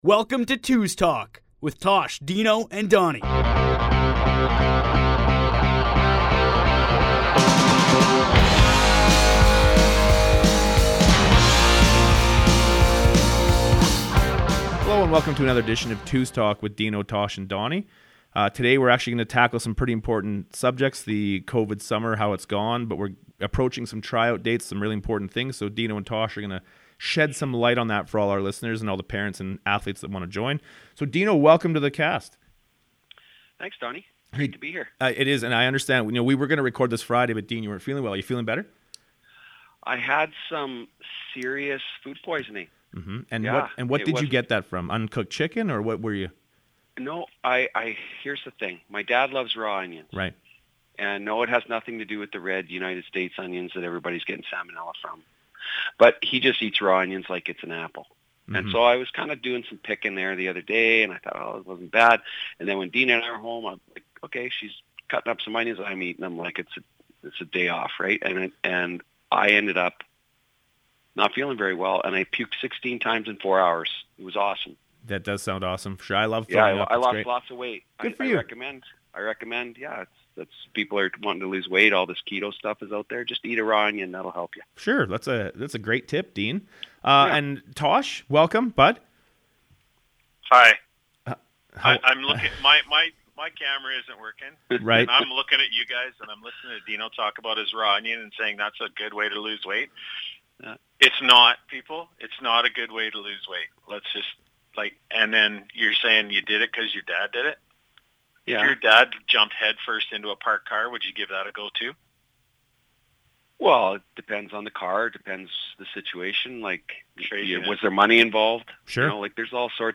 This week, the boys talk about plans moving forward for the 2’s program regarding the Travel Teams Tryouts, Winter Training, and what to expect this fall as we all navigate these uncertain times. New athletes and parents to the 222’s Travel Team tryout process will get a breakdown of the camp to help understand everything that happens on the day. Many questions will be answered and of course, there will be a few laughs as always.